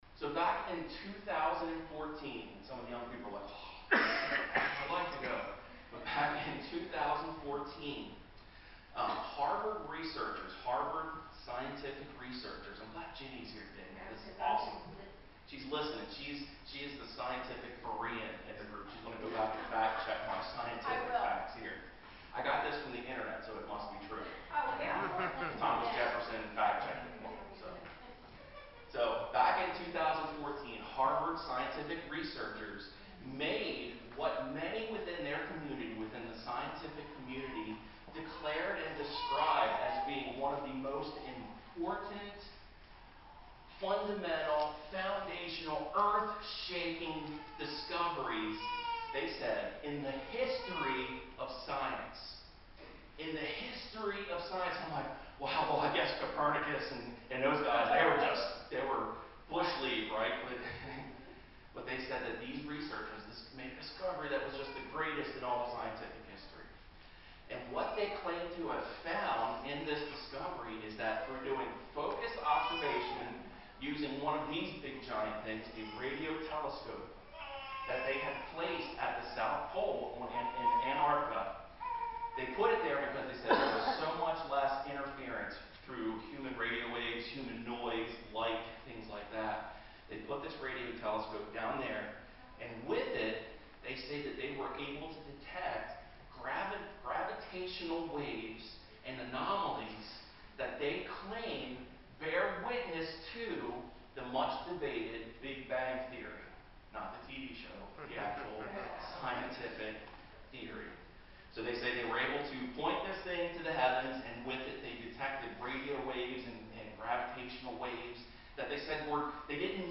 We apologize for the poor audio quality, we suffered technical difficulties with the recording this week.